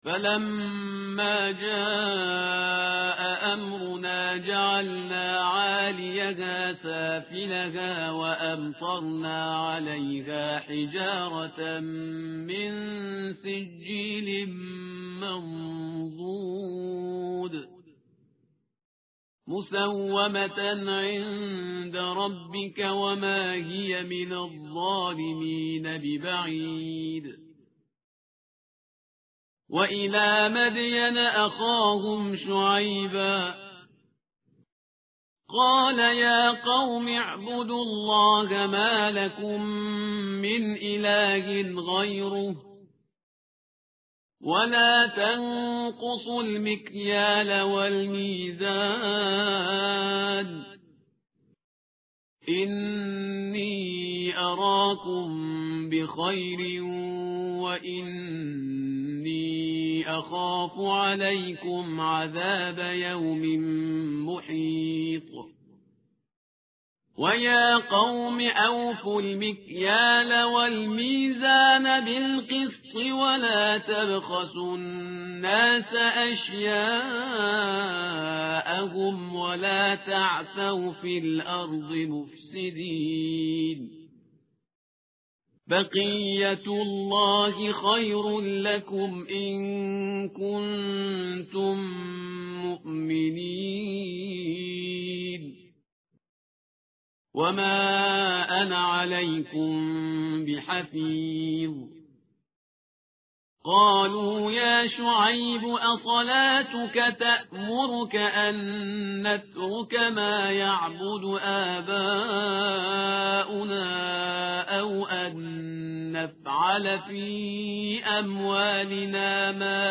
متن قرآن همراه باتلاوت قرآن و ترجمه
tartil_parhizgar_page_231.mp3